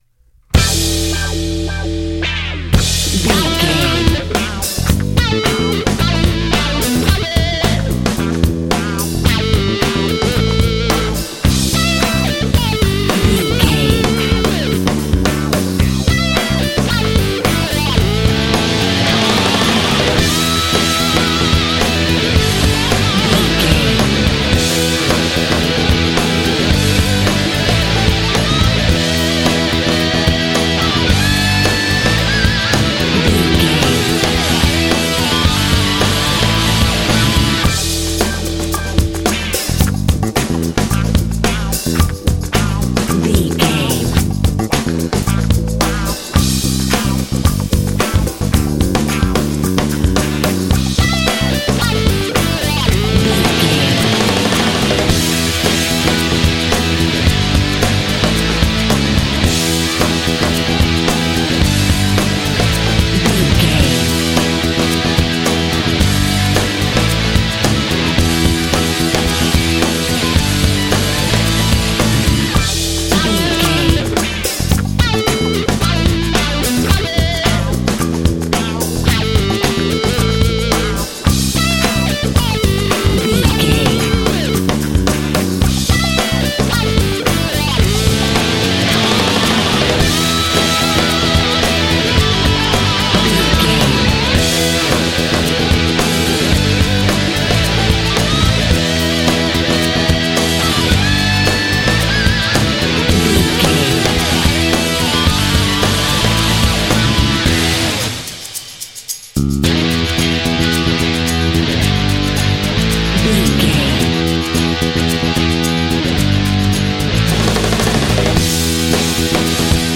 Fast paced
Aeolian/Minor
groovy
energetic
drums
electric guitar
bass guitar
rock
heavy metal